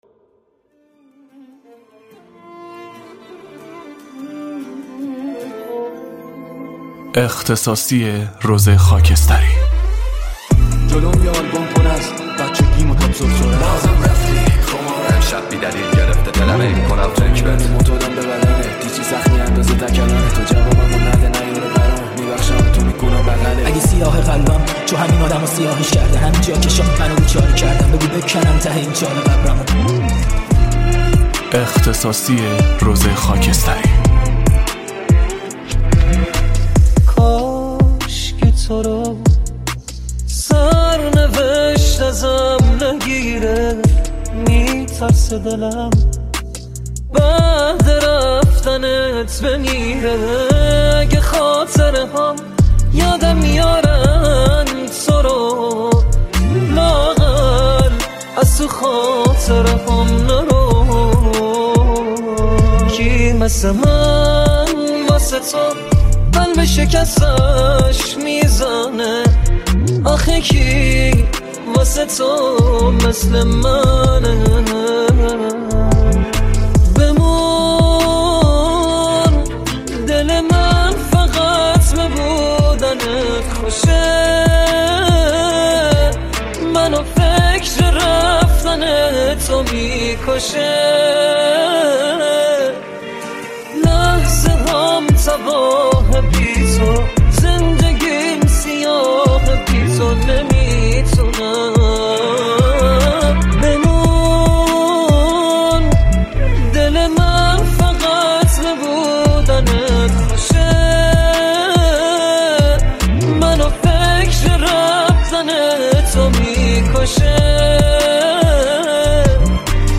ریمیکس شاد